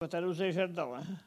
Soullans
Catégorie Locution